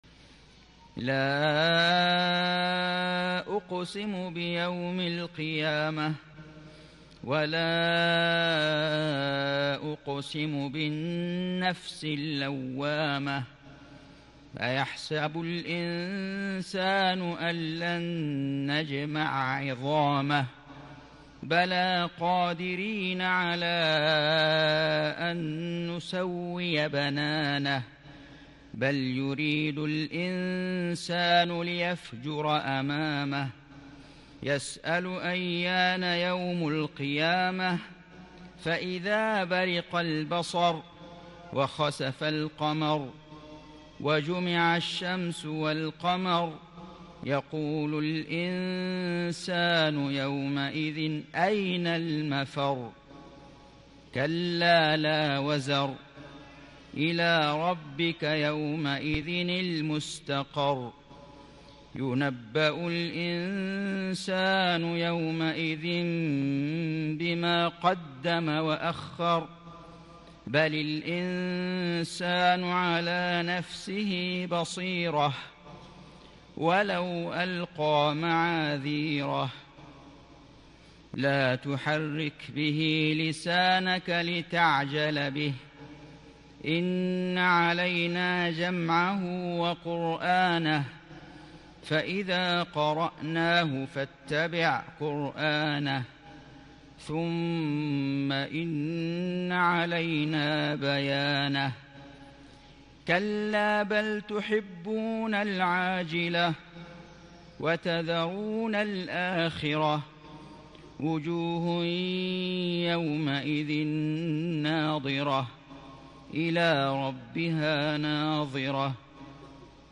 سورة القيامة > السور المكتملة للشيخ فيصل غزاوي من الحرم المكي 🕋 > السور المكتملة 🕋 > المزيد - تلاوات الحرمين